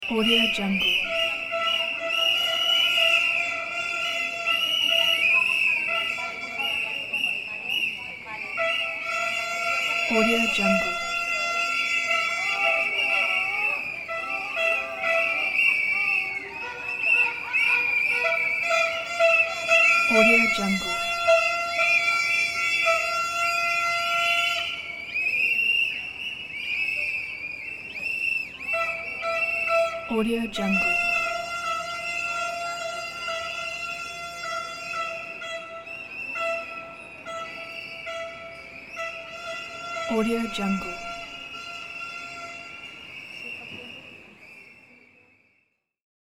دانلود افکت صدای جمعیت در مرکز شهر
به قلب شلوغ شهر خوش آمدید!
• ایجاد جو شهری و پویا: صدای قدم‌های شتابان، صحبت‌های مردم، بوق ماشین‌ها و سایر صداهای محیطی مرکز شهر، حس شلوغی و زندگی شهری را به بیننده منتقل می‌کند و می‌تواند برای ایجاد جوهای شهری، اکشن یا دراماتیک در ویدیوهای شما استفاده شود.
• کیفیت بالا: این فایل صوتی با کیفیت بالا ضبط شده است تا بهترین تجربه صوتی را برای شما فراهم کند.
16-Bit Stereo, 44.1 kHz